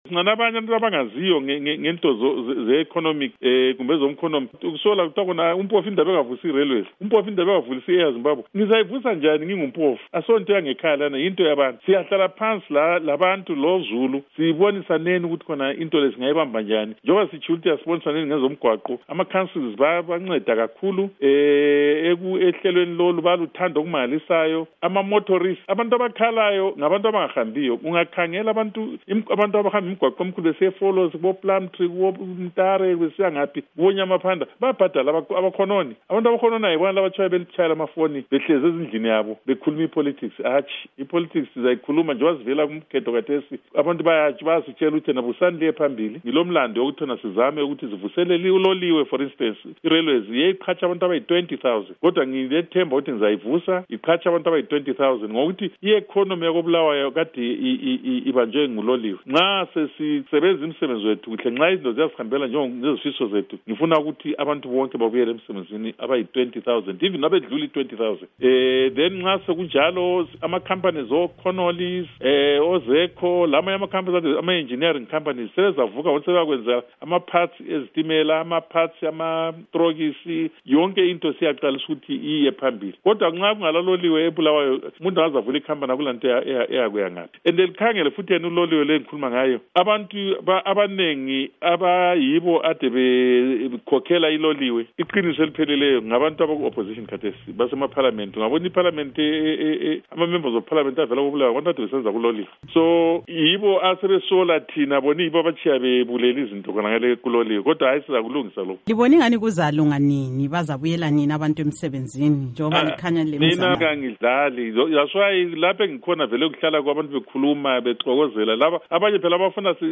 Ingxoxo LoMnu. Obert Mpofu